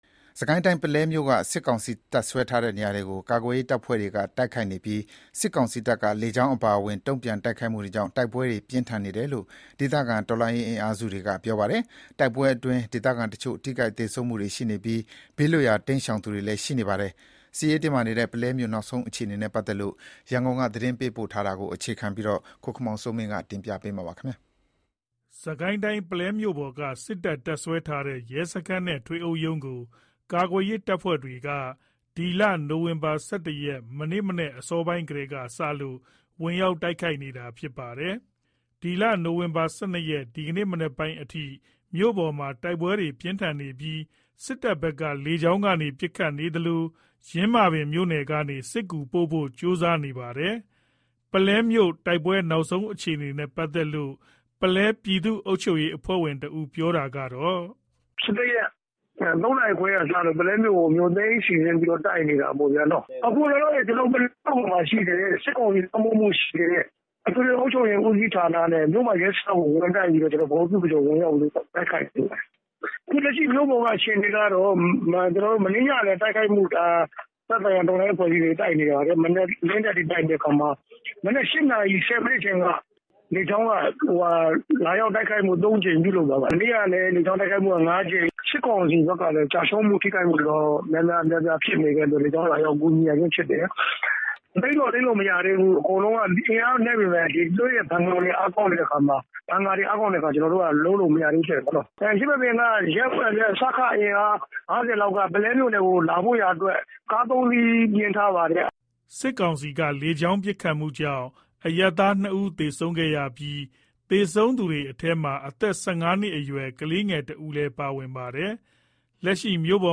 ပုလဲမြို့ တိုက်ပွဲနောက်ဆုံးအခြေအနေနဲ့ ပတ်သက်လို့ ပုလဲပြည်သူ့အုပ်ချုပ်ရေးအဖွဲ့ဝင်တဦးက အခုလို ပြောပါတယ်။